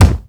punch_low_deep_impact_02.wav